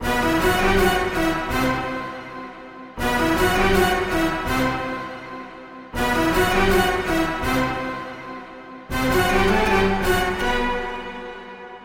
描述：电影乐谱简介/ outro的弦乐合奏
标签： 鞠躬 低音提琴 得分 合奏 管弦乐队 经典
声道立体声